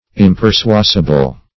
Search Result for " impersuasible" : The Collaborative International Dictionary of English v.0.48: Impersuasible \Im`per*sua"si*ble\, a. [Pref. im- not + persuasible: cf. OF. impersuasible.]